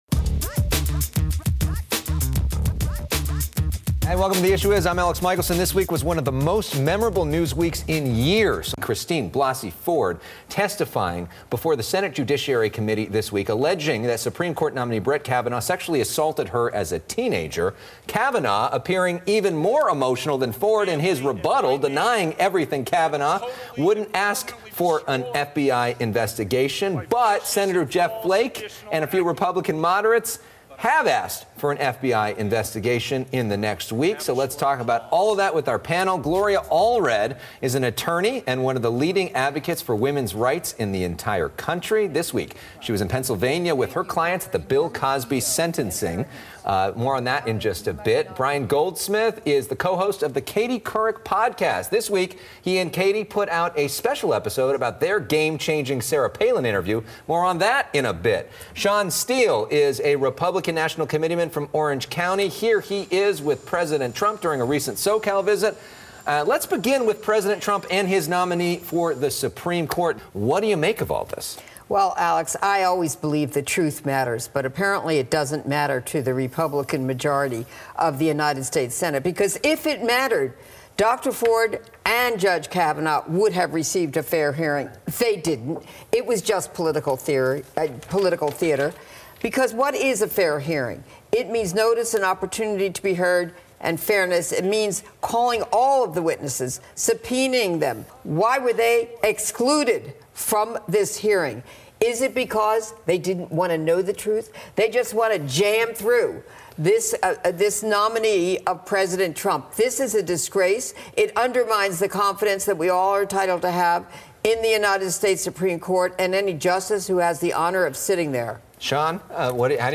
broadcast from FOX 11 Studios in Los Angeles.